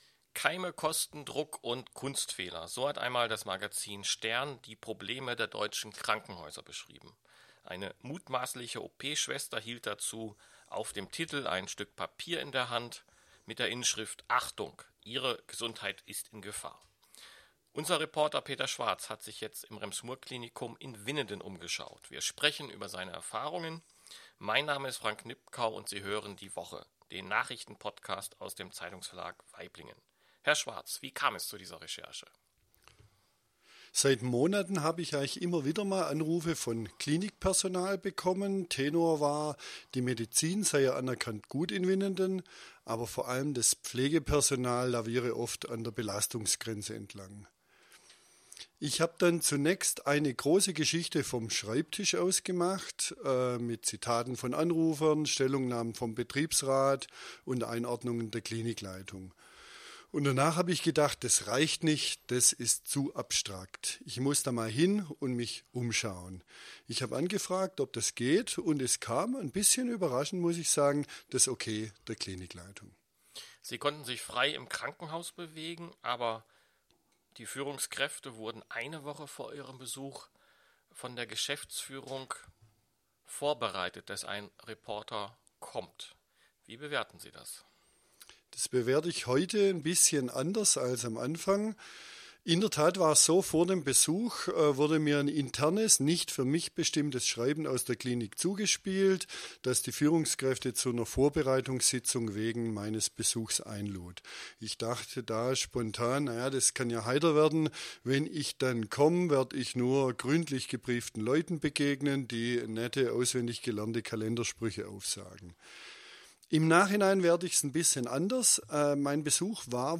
Keime, Kostendruck und Kunstfehler - so hat einmal das Magazin "Stern" die Probleme der deutschen Krankenhäuser beschrieben. 11 Minuten 10.12 MB Podcast Podcaster Die Woche Die Woche ist der Nachrichten-Podcast aus dem Zeitungsverlag Waiblingen.